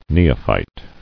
[ne·o·phyte]